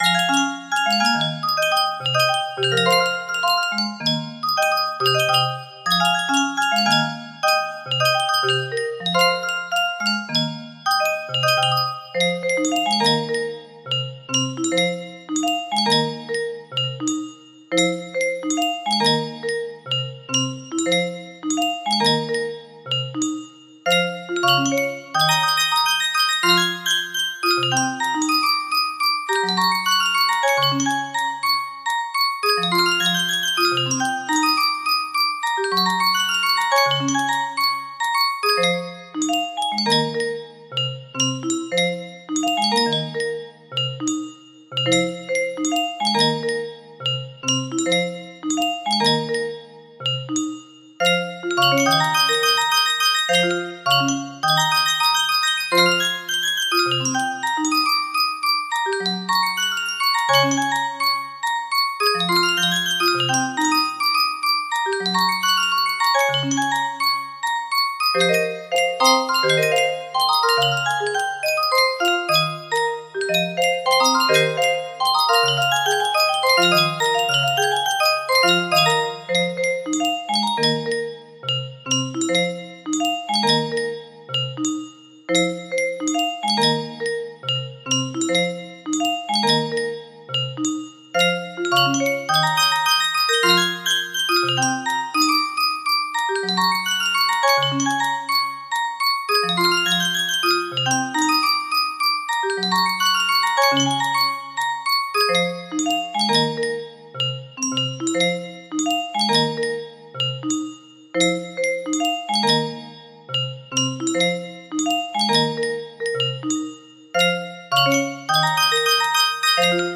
Full range 60
(SNES) - Stage 1 Theme Exagerrated for lulz...